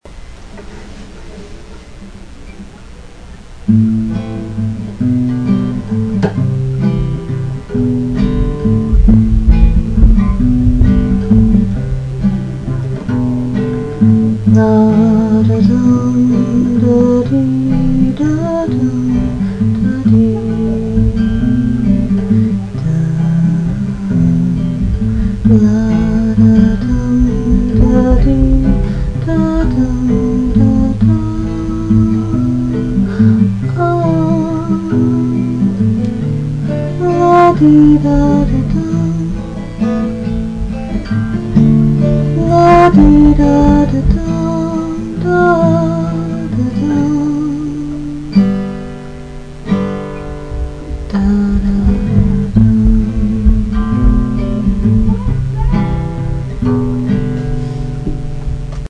Warning: I've Never claimed to be able to sing well or play the guitar well. I'm only putting these up so the curious have an idea how the melodies would be (can't read or write music). I recorded it with my sister's microphone on my laptop.